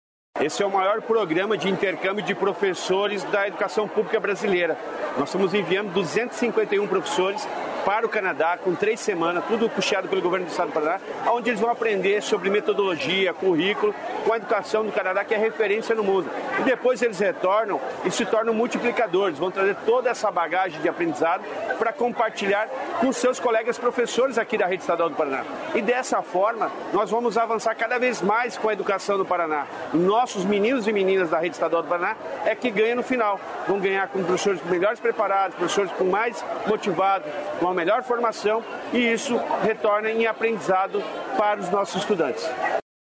Sonora do secretário da Educação, Roni Miranda, sobre o intercâmbio dos professores no Canadá